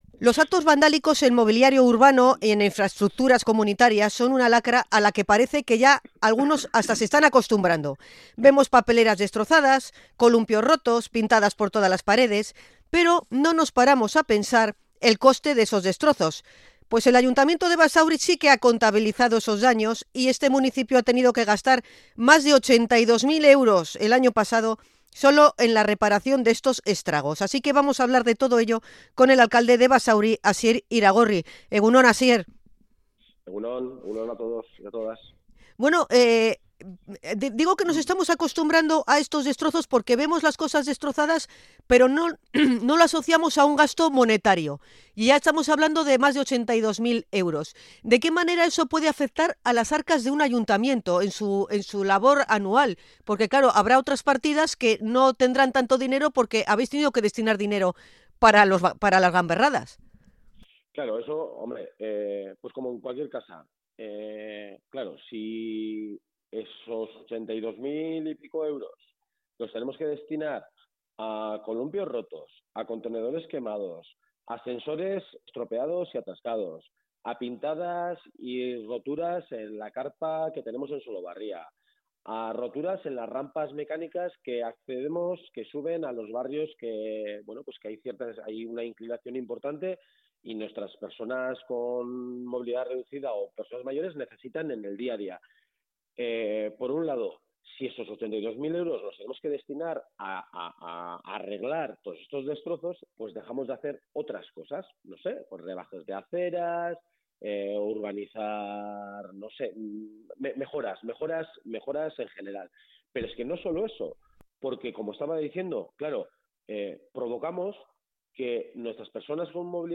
Hablamos con Asier Iragorri, alcalde de Basauri
INT.-ACTOS-VANDALICOS-BASAURI-.mp3